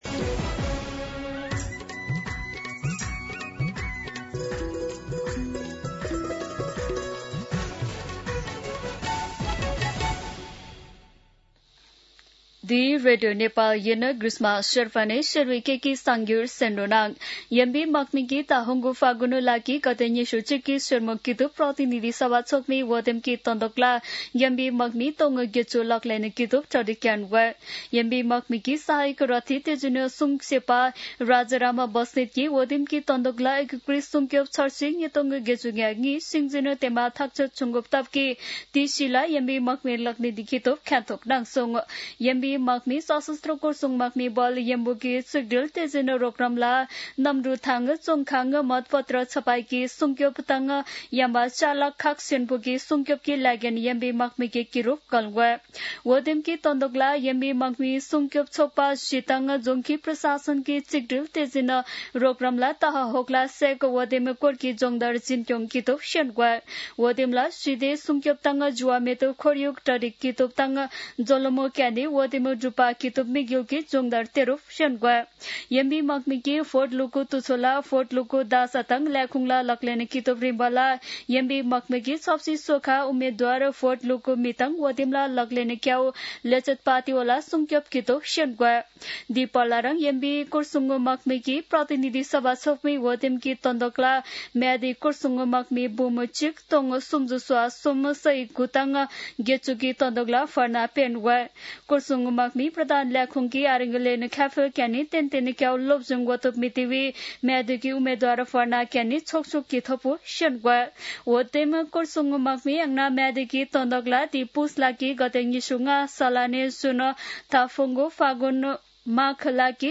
शेर्पा भाषाको समाचार : २४ पुष , २०८२
Sherpa-News-09-24.mp3